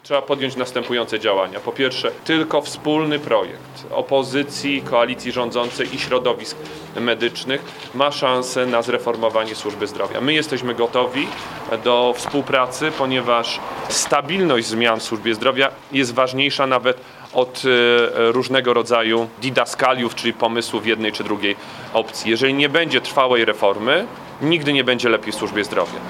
Nieprzypadkowo miejscem spotkania w Olecku był teren tamtejszego szpitala. PSL ma propozycje dla rządu, która może pomóc obsadzić wakaty lekarzy. O tym między innymi powiedział szef ludowców.